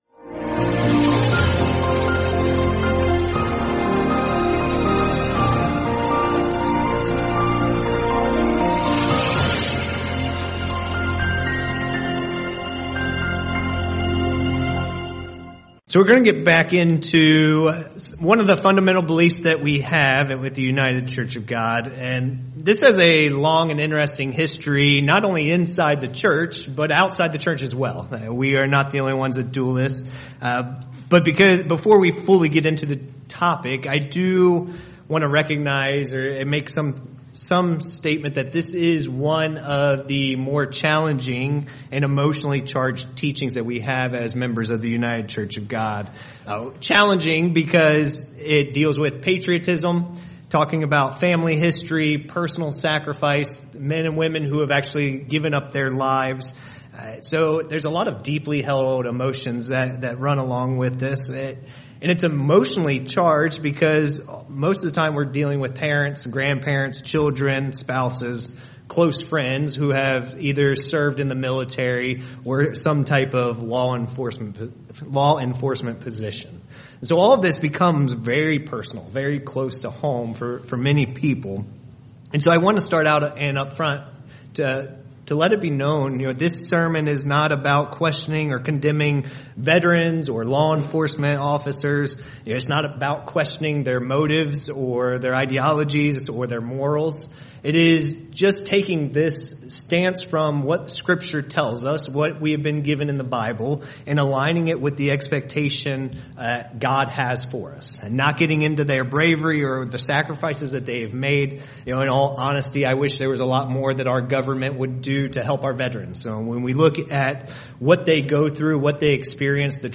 Sermons Military Service and War